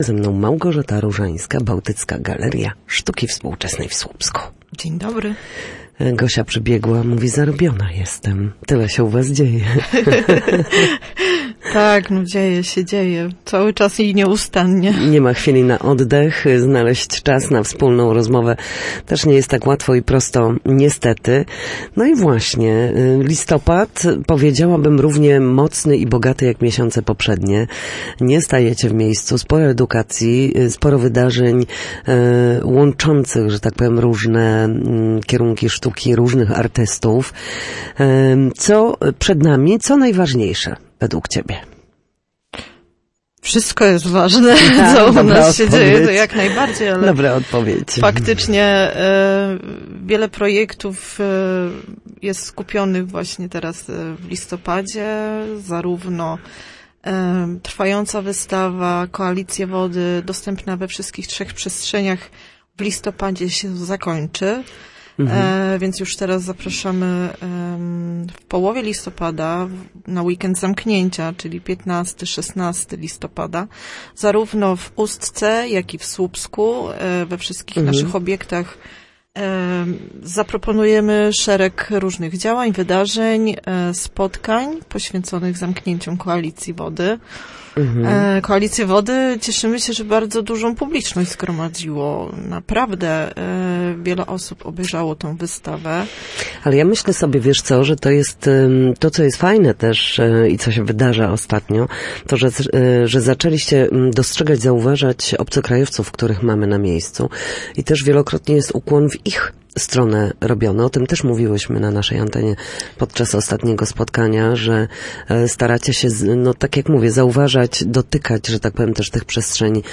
O wydarzeniach zaplanowanych w instytucji na najbliższy miesiąc mówiła na naszej antenie